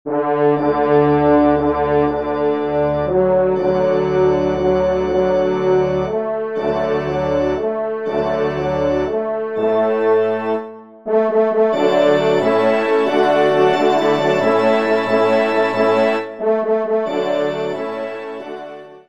ENSEMBLE